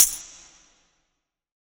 MZ Tambo [Metro Tambo #1].wav